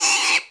monster / wild_boar / damage_1.wav
damage_1.wav